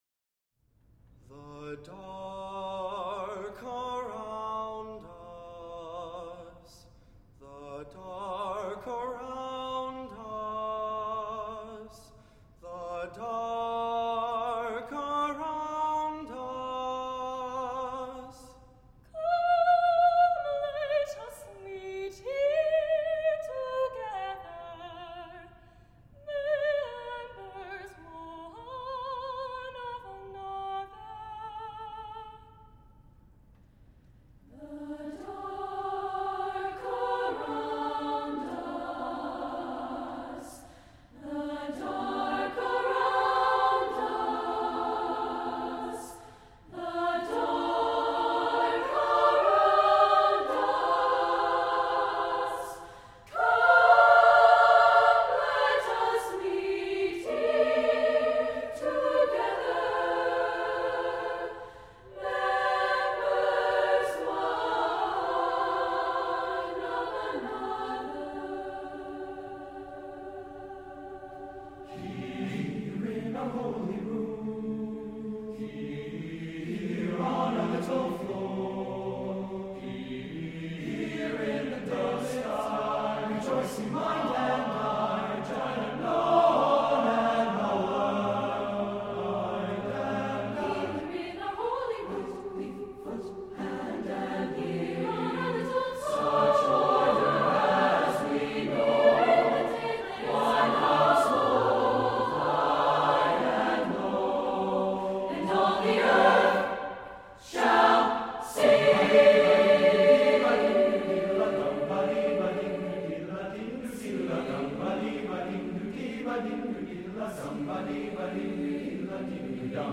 A folk-inspired piece calling for the unity of humankind.
SATB a cappella